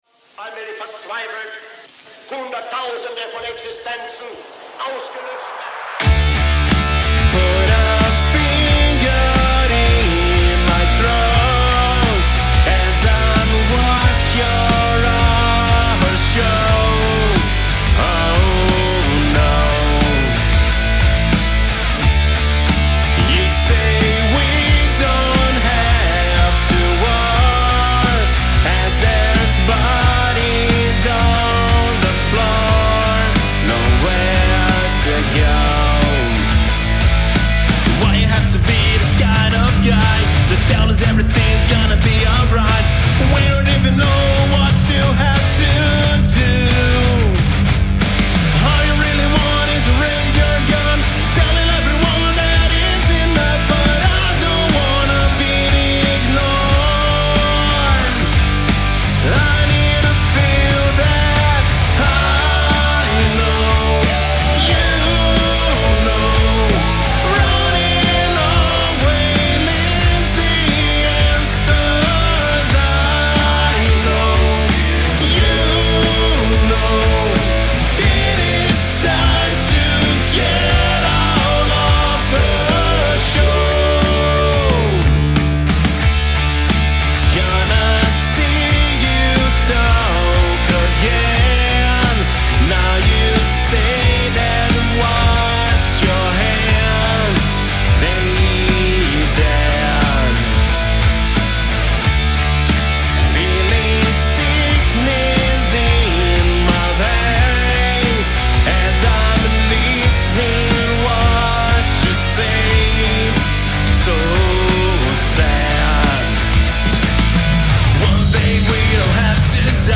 EstiloHard Rock